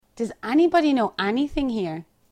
Examples of English from the Irish Republic
//dʌz ʹænibɑdi noː ʹænit̪ɪŋ hiːr//
We have examples here of the pronunciation of anybody and anything with /æ/ rather than /e/.